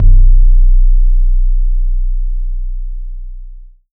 BLUE BASS -R.wav